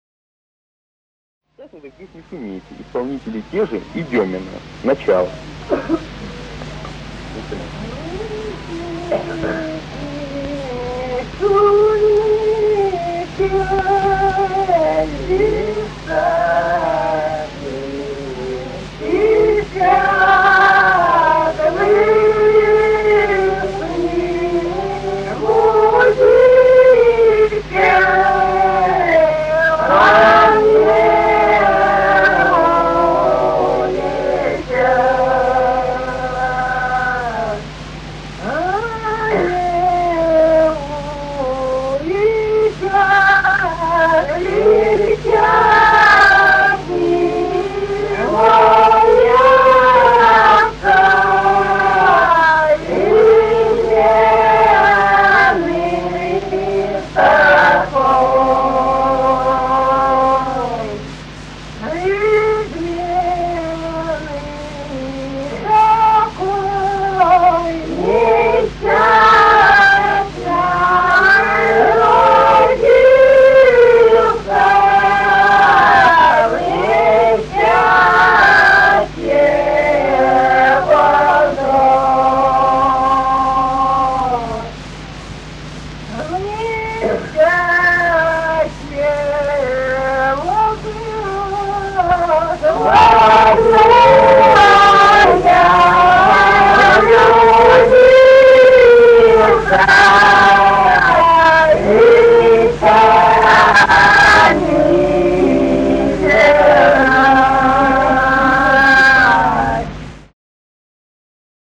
Русские народные песни Владимирской области 31. Вы здесь не шумите (лирическая) с. Михали Суздальского района Владимирской области.